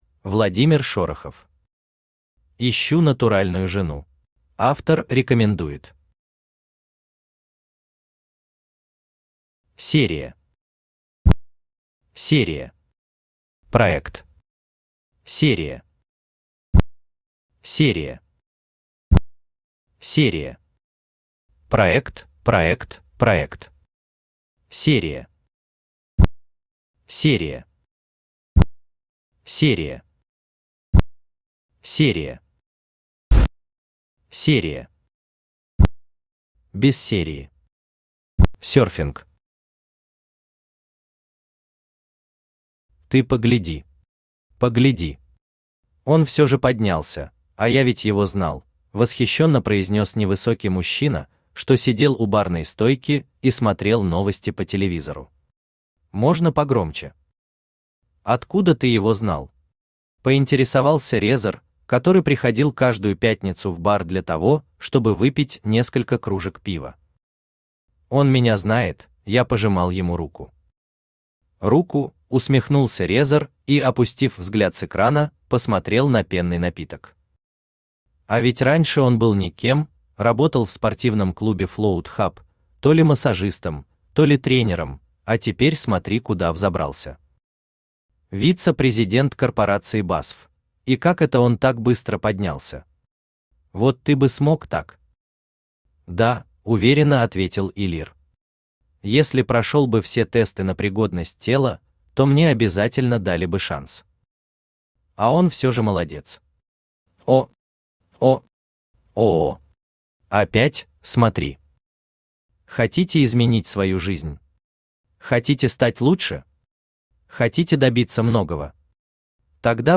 Aудиокнига Ищу натуральную жену Автор Владимир Леонидович Шорохов Читает аудиокнигу Авточтец ЛитРес.